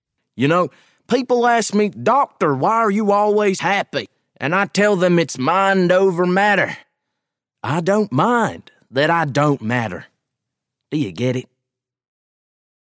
Southern